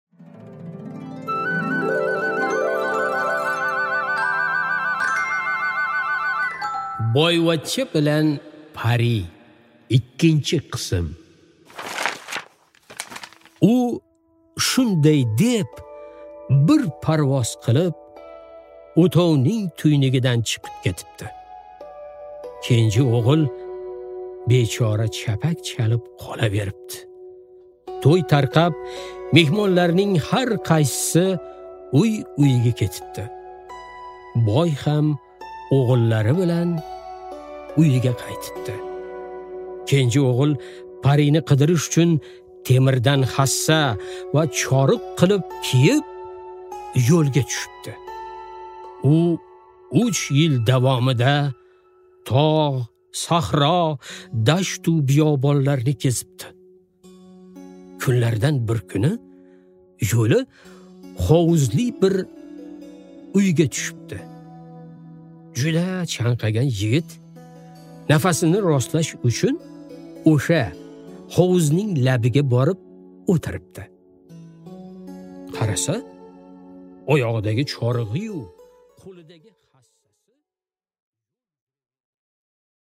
Аудиокнига Boyvachcha bilan pari. 2-qism | Библиотека аудиокниг